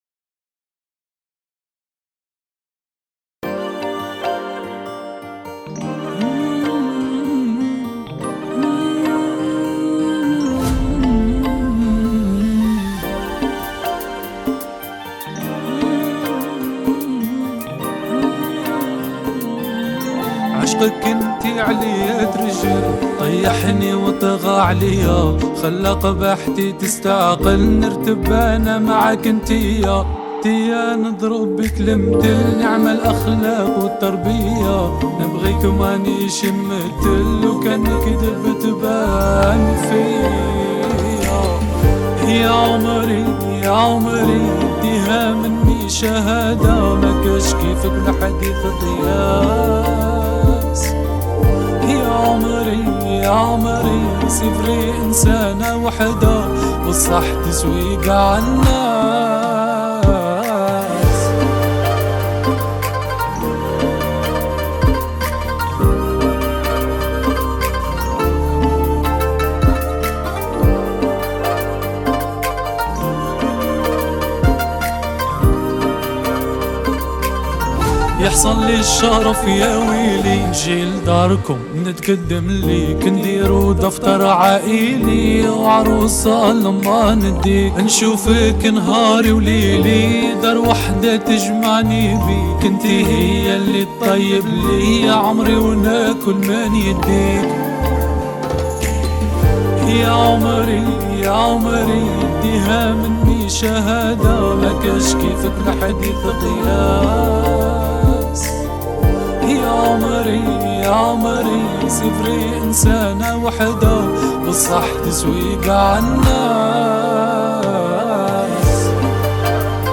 اغاني جزائرية